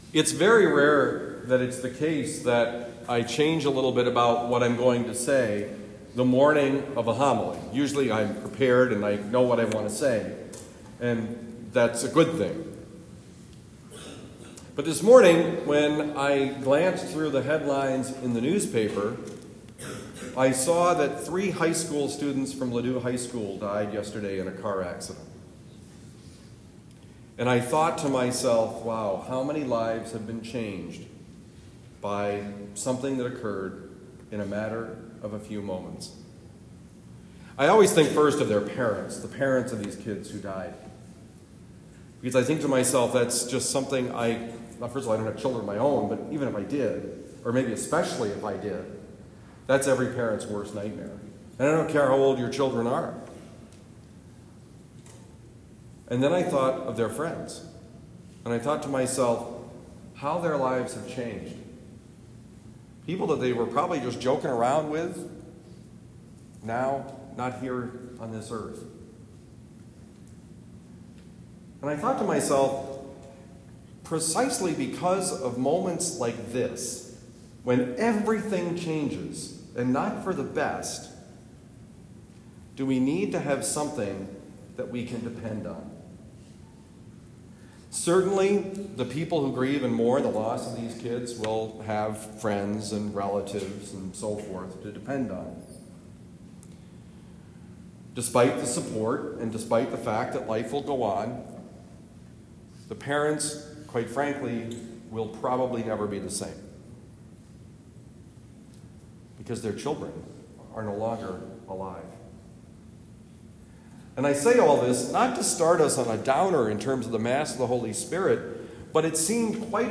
Given at Christian Brothers College High School, Town and Country, Missouri.